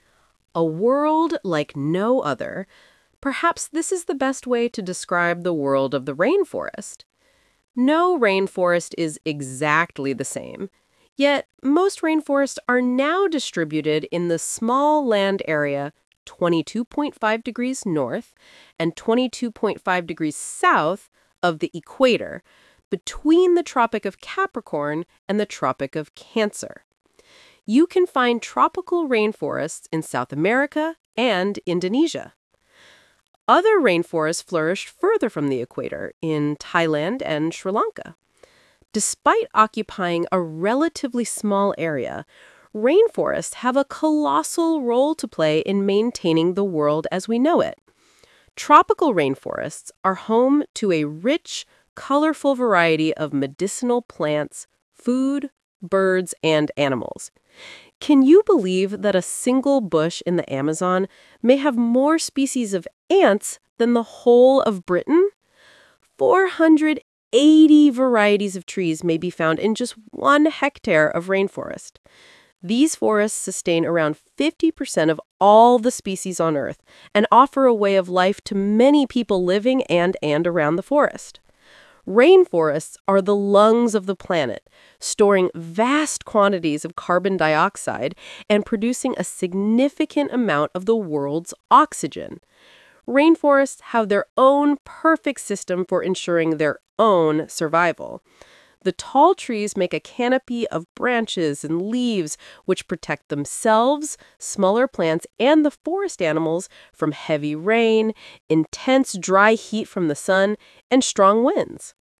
Talk/Lecture 3: You will hear a talk about rainforests.